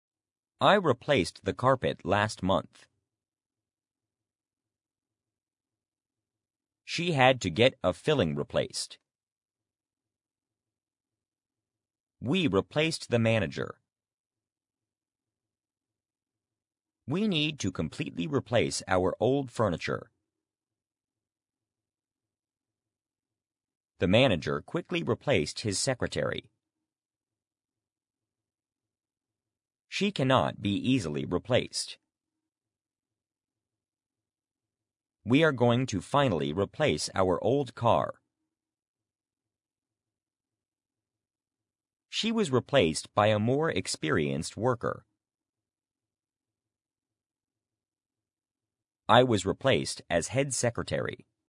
replace-pause.mp3